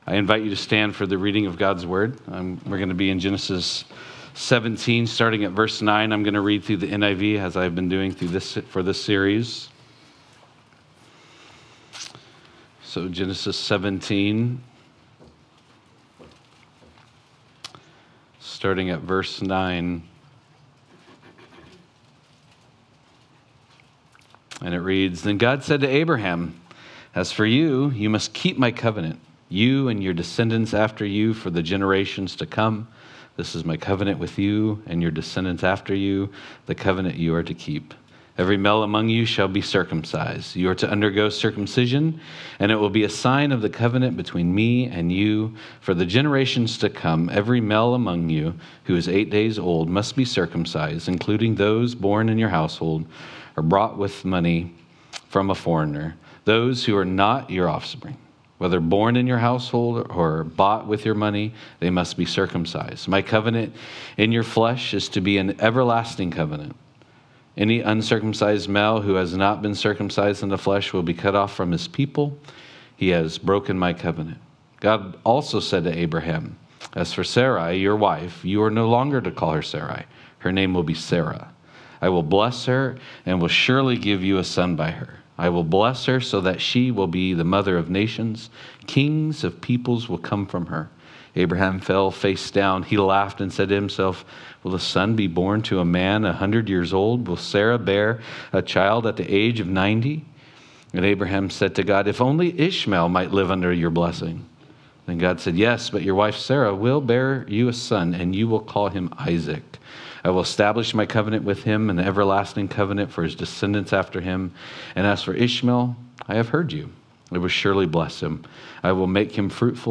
Genesis Service Type: Sunday Morning « Reason for Baptism Genesis-In the Beginning